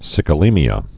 (sĭkə-lēmē-ə)